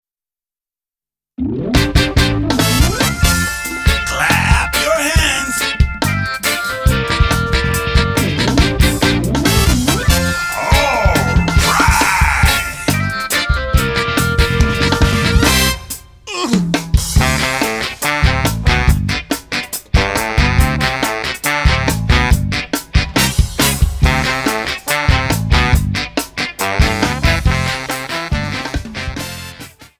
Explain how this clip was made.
Trim and fade